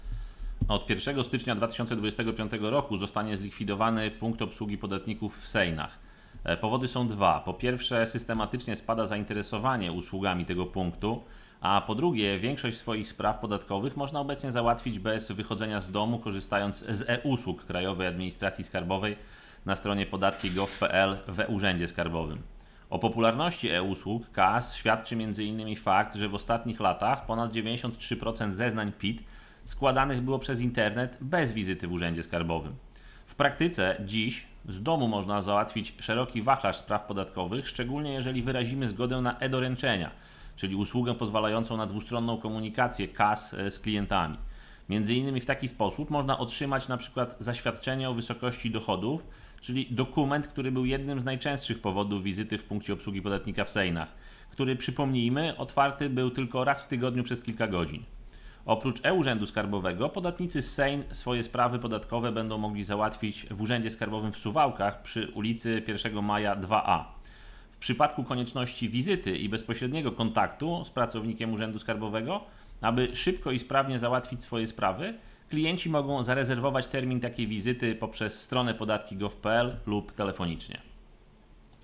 Likwidacja Punktu Obsługi Podatnika w Sejnach (wypowiedź